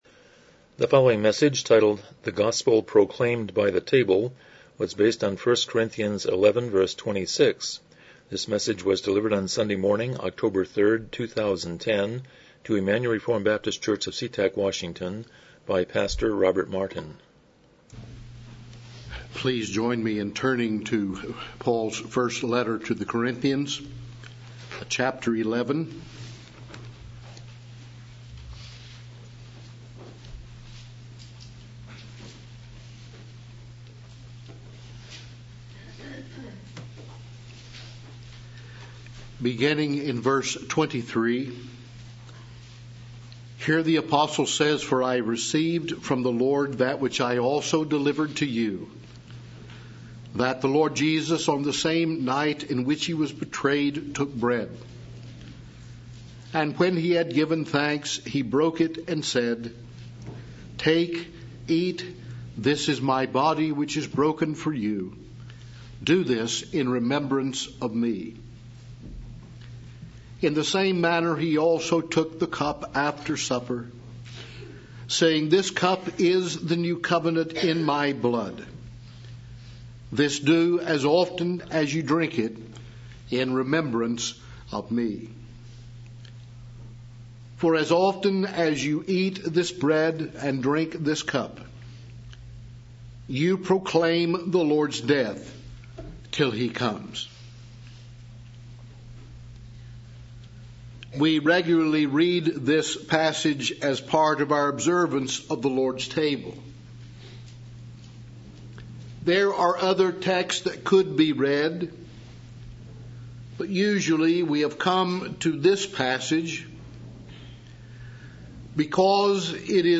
Passage: 1 Corinthians 11:26 Service Type: Morning Worship